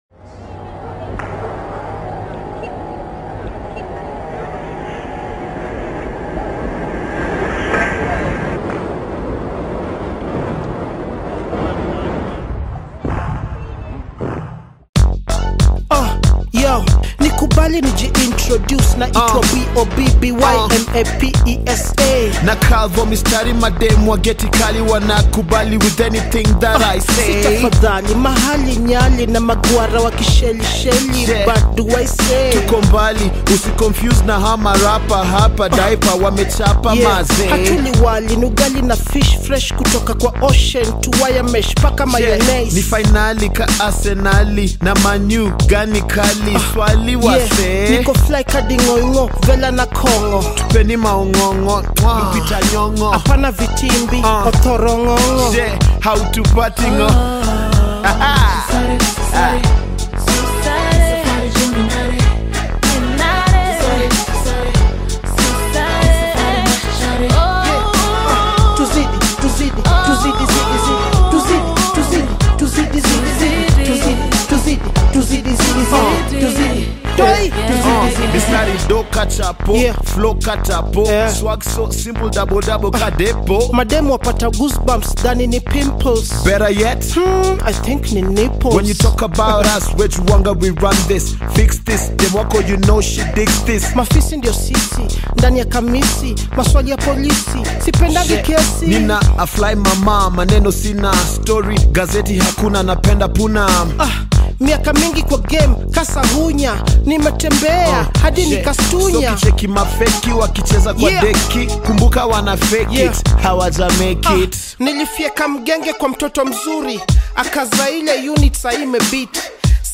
Any hip hop fan will love this